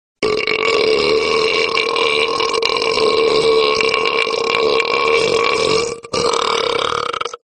Burp Sound Effect Free Download
Burp